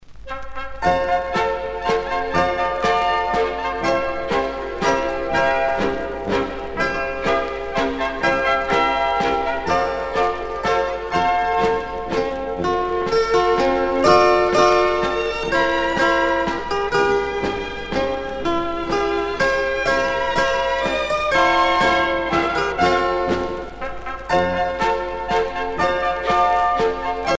danse : ländler
Pièce musicale éditée